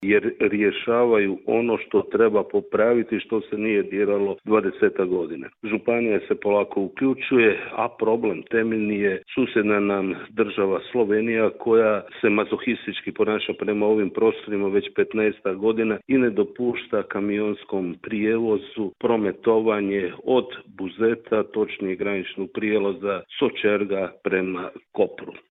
Gradonačelnik Buzeta Damir Kajin kazao je kako Hrvatske ceste nisu problem, već su oni rješenje problema.